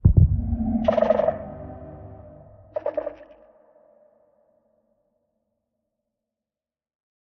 Minecraft Version Minecraft Version latest Latest Release | Latest Snapshot latest / assets / minecraft / sounds / mob / warden / nearby_closest_1.ogg Compare With Compare With Latest Release | Latest Snapshot